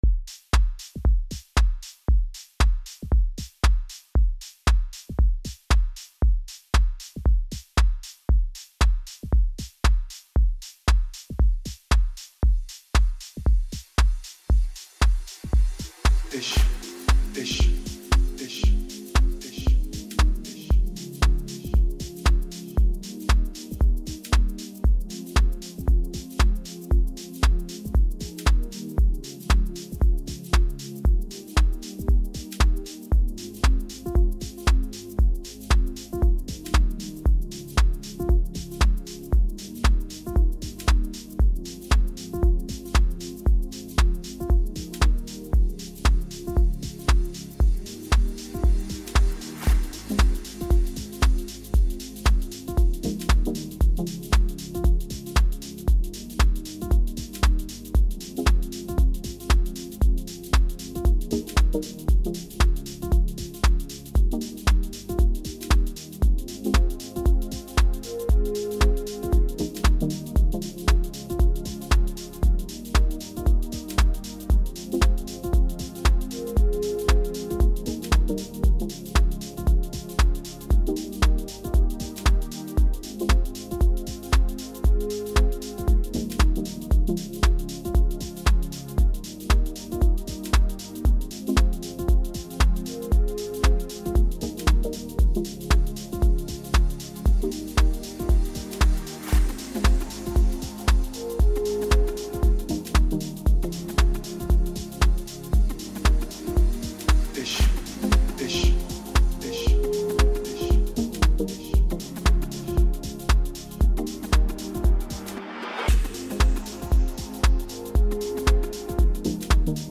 Home » Amapiano » DJ Mix » Hip Hop
South African singer